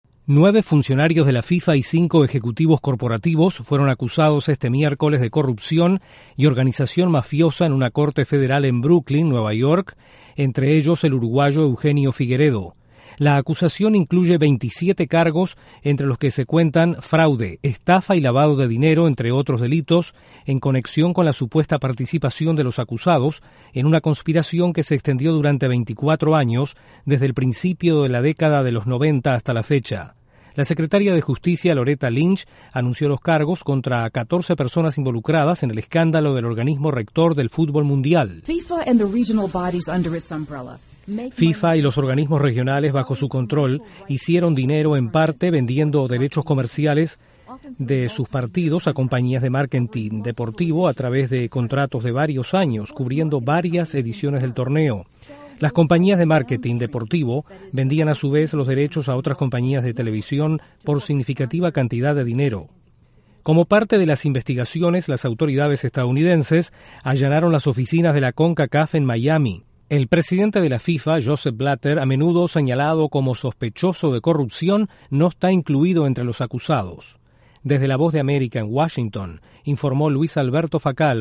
Estados Unidos acusó a 14 personas este miércoles por corrupción en la FIFA. Desde la Voz de América en Washington informa